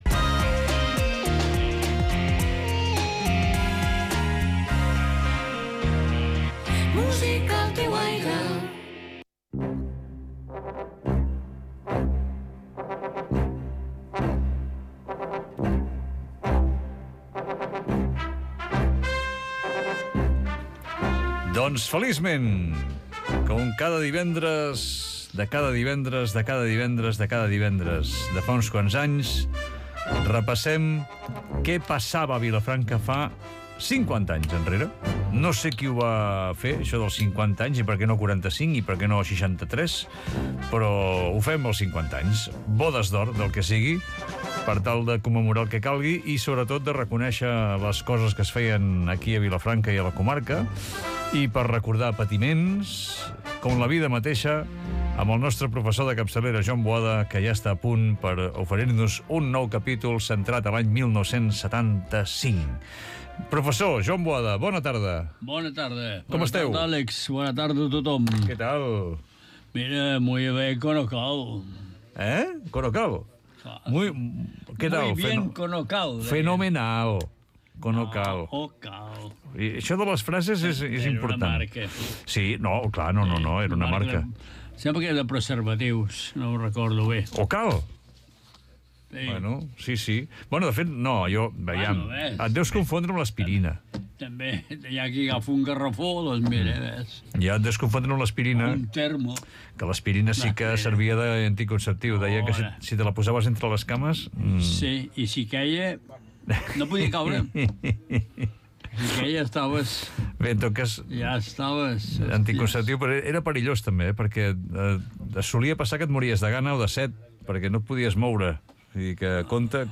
Entrevista als Grass is greener 3/7/25